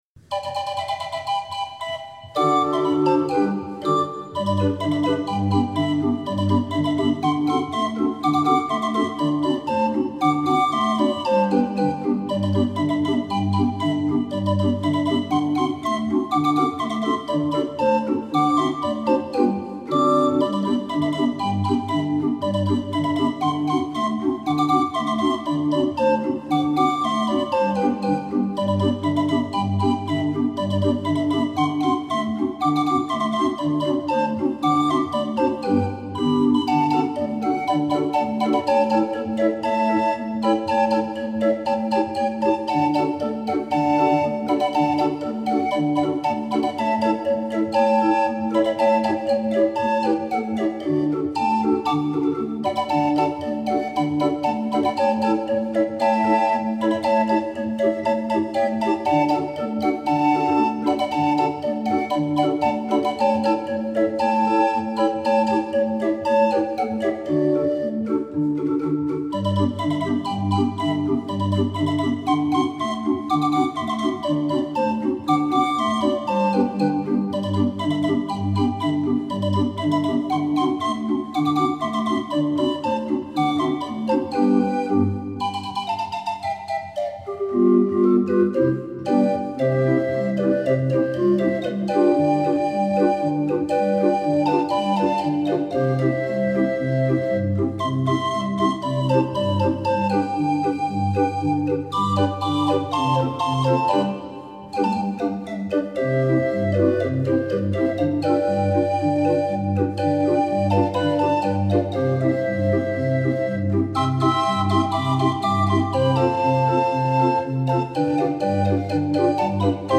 26 street organ